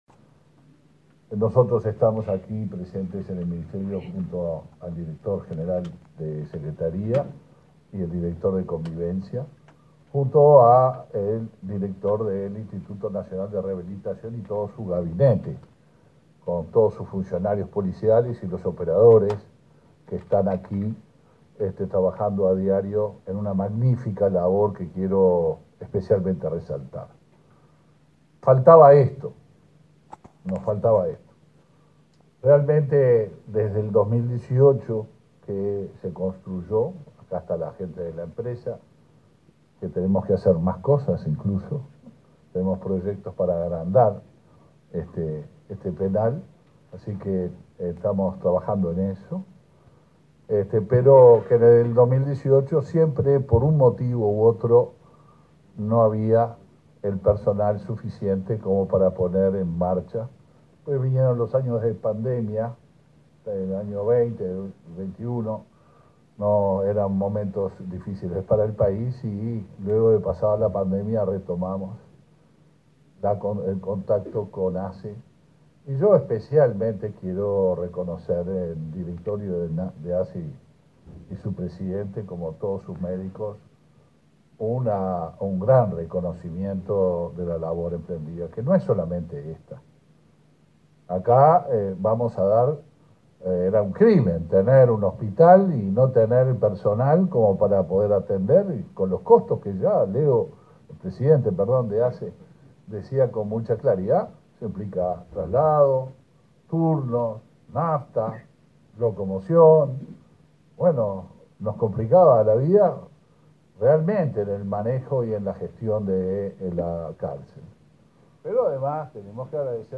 Palabras del ministro del Interior, Luis Alberto Heber
El Ministerio del Interior y la Administración de los Servicios de Salud del Estado (ASSE) inauguraron, este martes 13, la sala de cuidados y rehabilitación del Instituto Nacional de Rehabilitación (INR) y el Sistema de Atención Integral de las Personas Privadas de Libertad, en la Unidad n.° 1. El ministro Luis Alberto Heber participó en el acto.